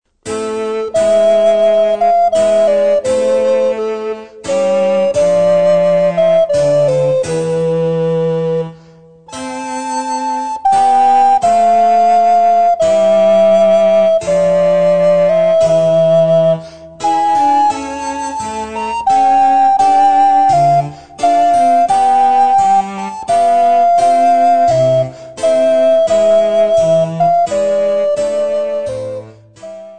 Besetzung: Altblockflöte und Basso continuo